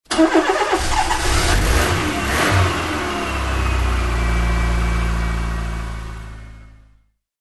Звуки легкового автомобиля
Шумный запуск мотора старого, но исправного автомобиля